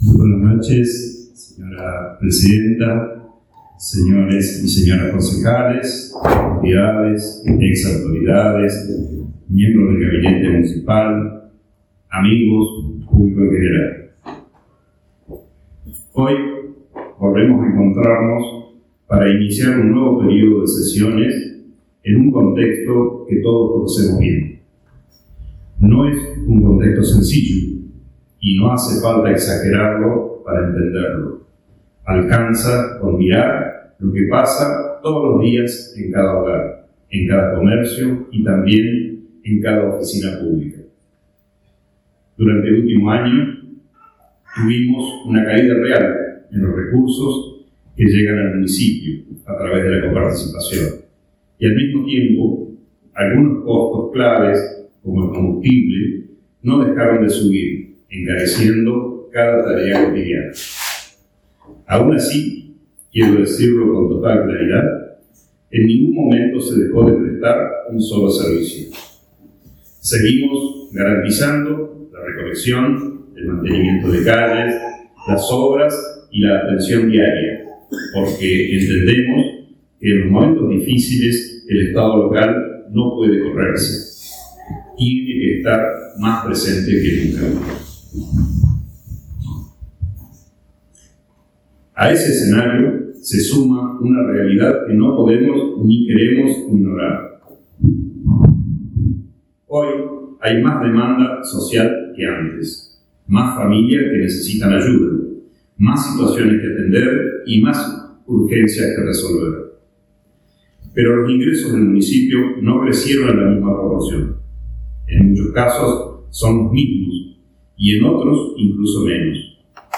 Discurso de apertura sesiones 2026 Concejo Deliberante de Victorica.
Desde las 19hs del 6 de marzo 2026 en el Salón de Actos de Victorica, se dio inicio a las sesiones ordinarias del Concejo Deliberante De Victorica.
Discurso del intendente Hugo Kenny